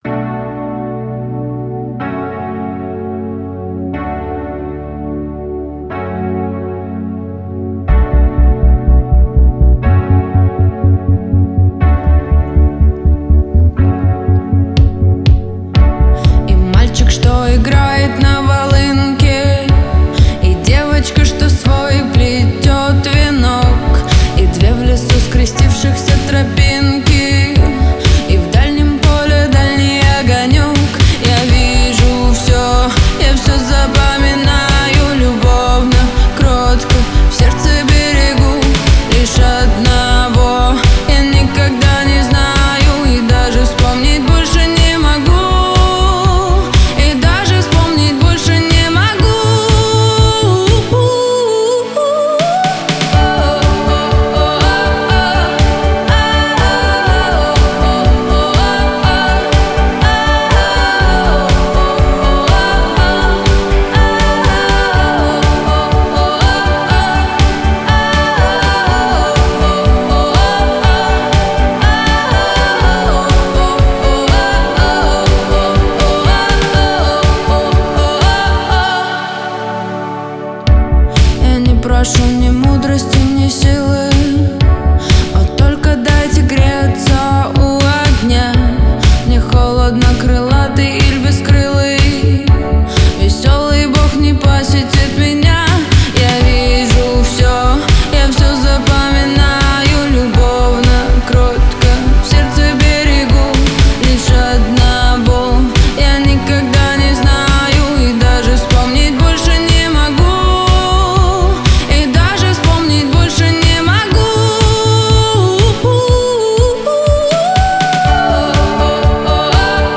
это трогательная песня в жанре поп-фолк